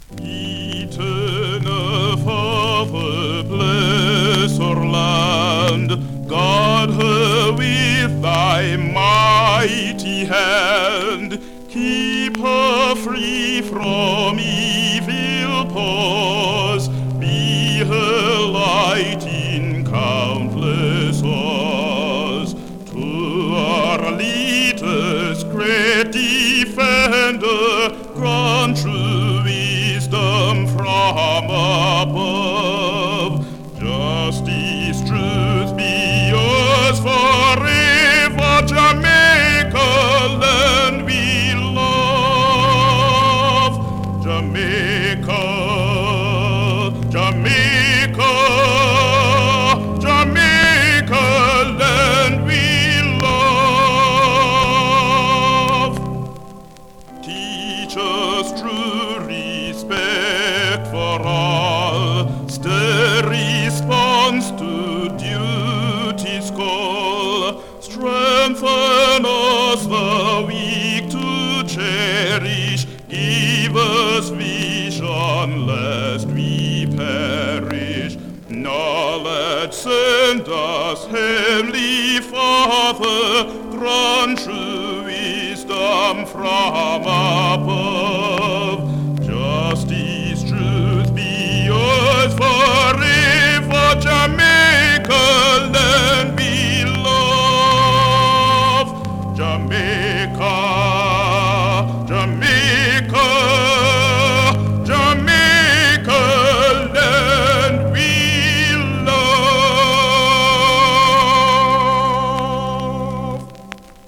型番 SIDE 2:VERSION/VG+
スリキズ、ノイズ比較的少なめで